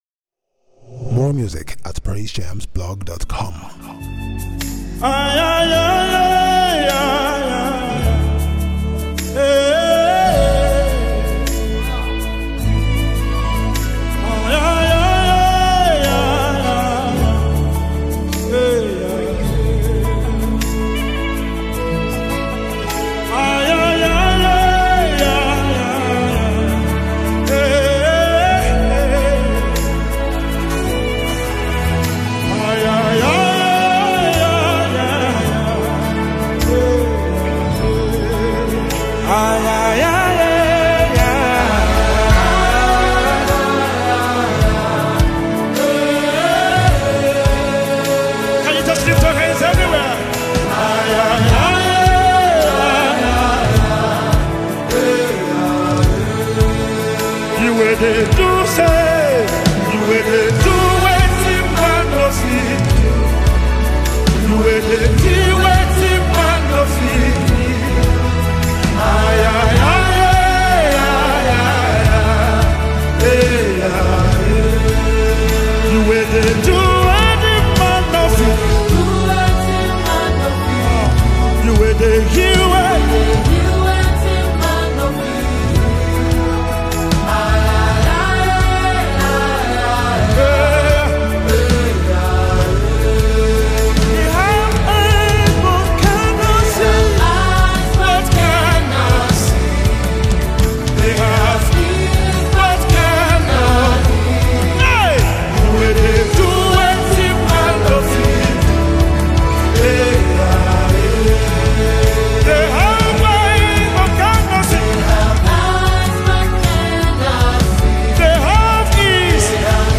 soul-lifting worship song